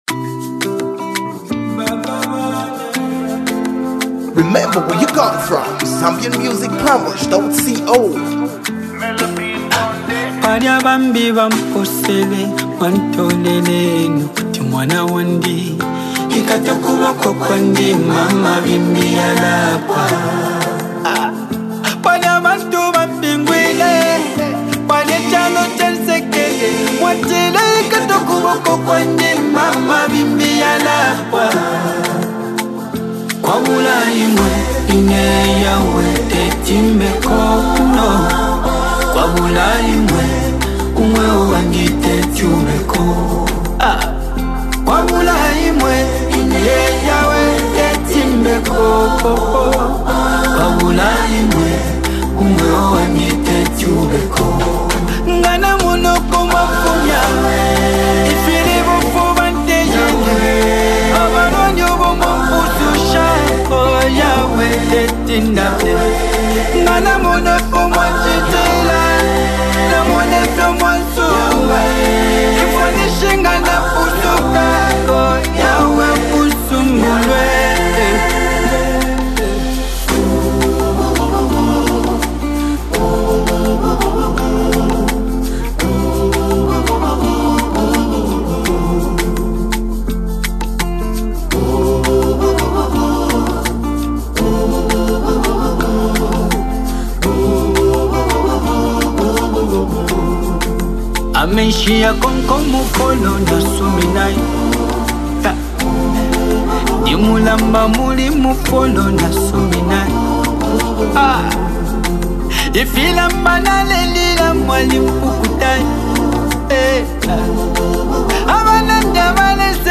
powerful and soulful masterpiece